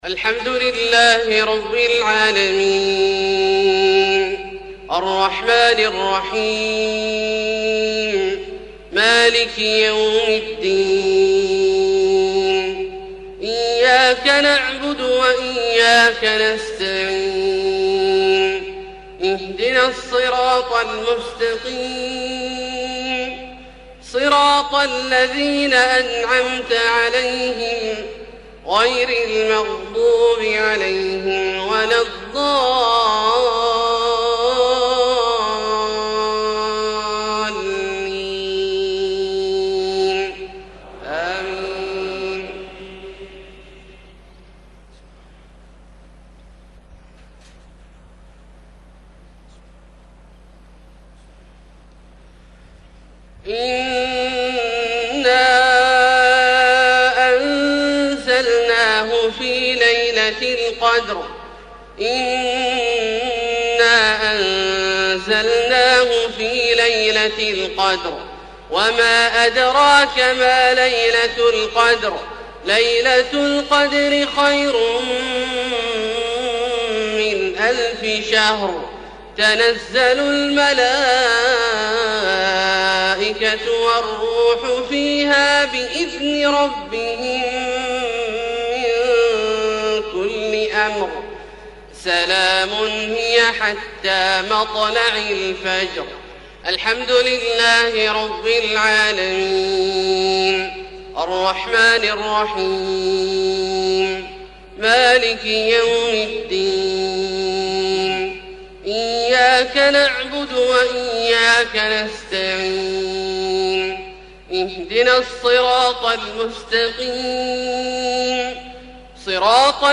Maghrib prayer Surat Al-Qadr and An-Nasr 16/12/2011 > 1433 H > Prayers - Abdullah Al-Juhani Recitations